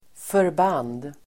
Uttal: [förb'an:d]